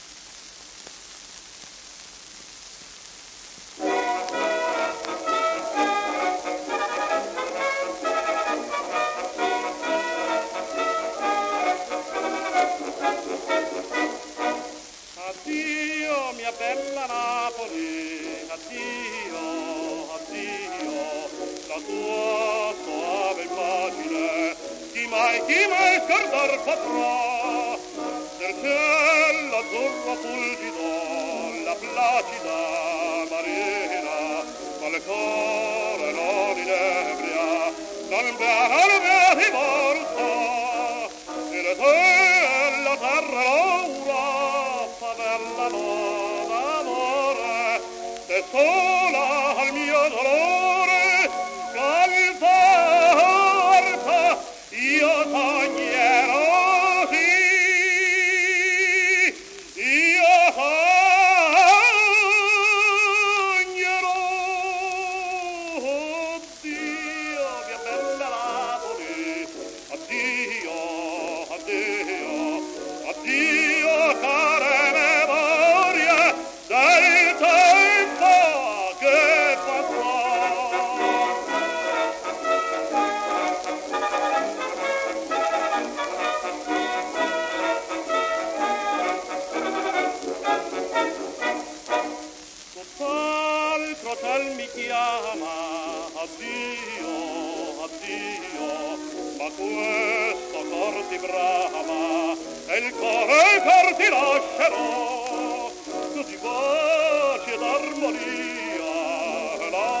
w/オーケストラ
10インチ片面盤
旧 旧吹込みの略、電気録音以前の機械式録音盤（ラッパ吹込み）